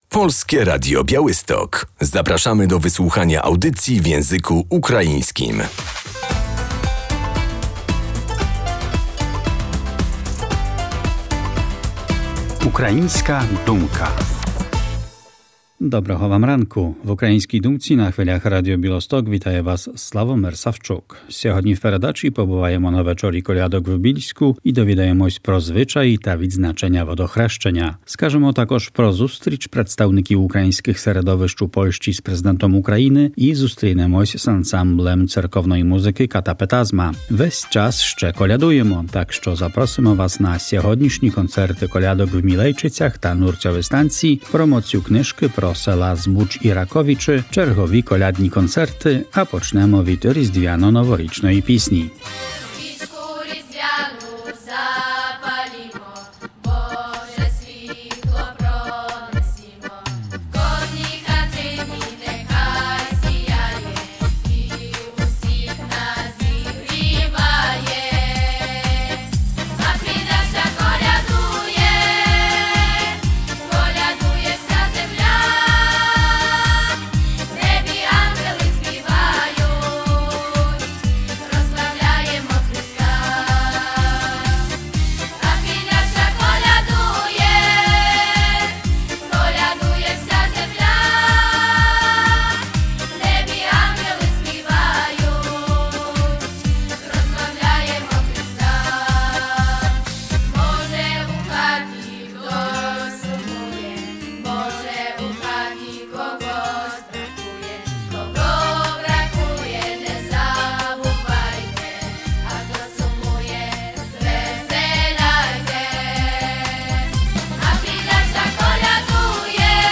W Bielskim Domu Kultury odbył się ukraiński Wieczór Kolęd – impreza z długą tradycją, od kilku lat w nowej formule.